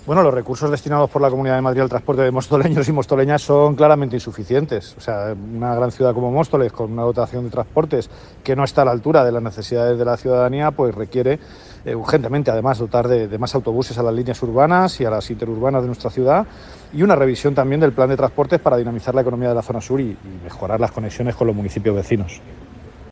declaraciones-emilio-delgado-alegaciones.mp3